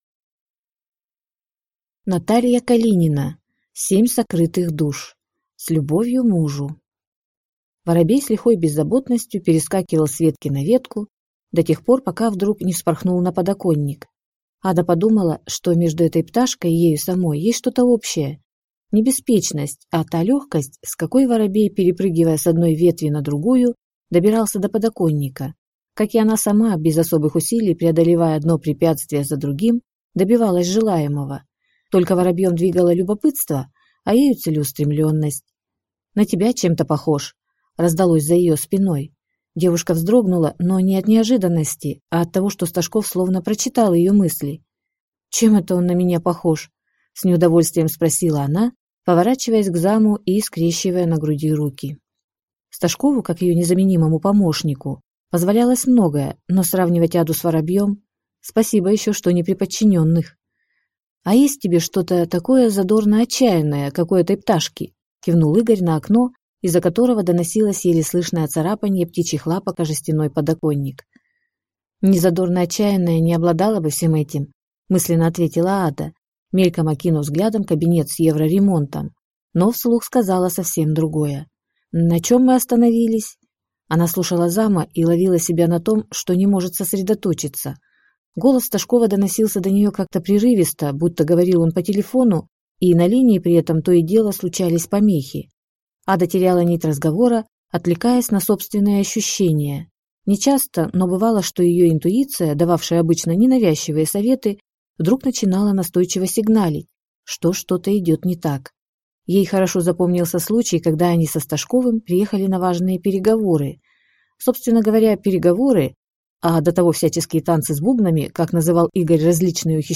Аудиокнига Семь сокрытых душ | Библиотека аудиокниг